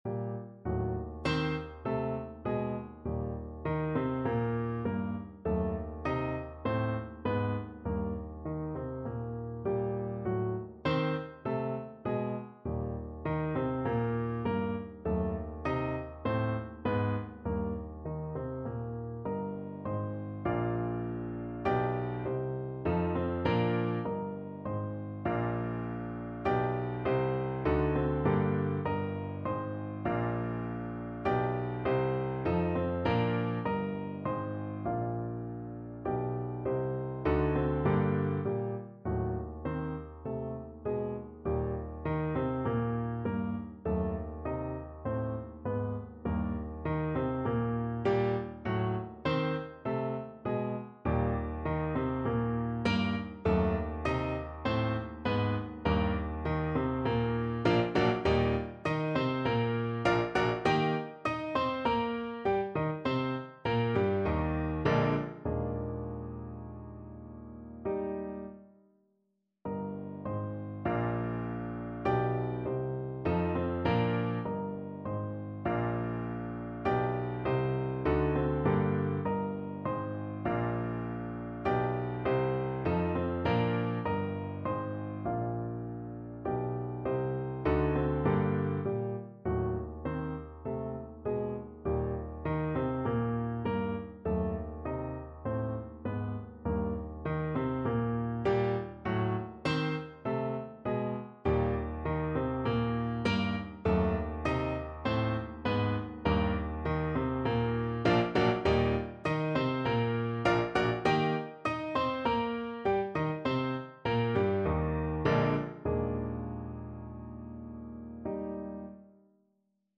No parts available for this pieces as it is for solo piano.
2/4 (View more 2/4 Music)
Moderato
Eb major (Sounding Pitch) (View more Eb major Music for Piano )
Piano  (View more Advanced Piano Music)
Classical (View more Classical Piano Music)